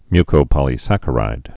(mykō-pŏlē-săkə-rīd)